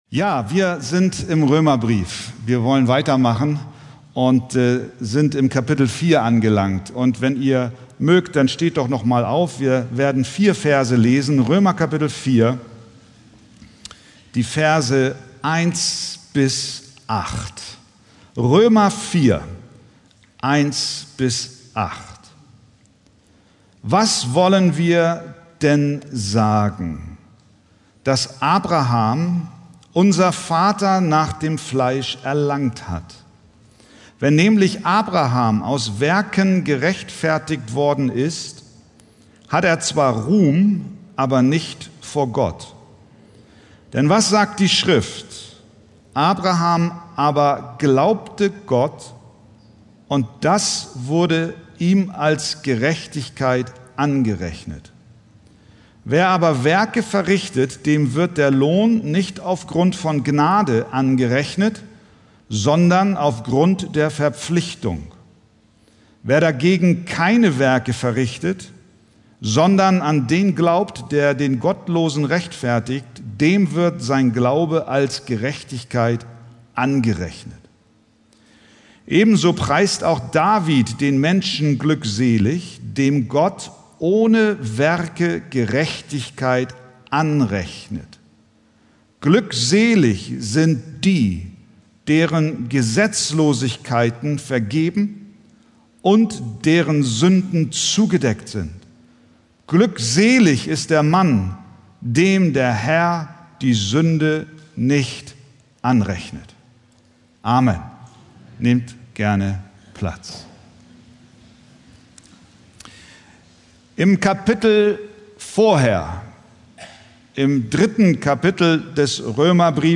Predigttext: Römer 4,1-8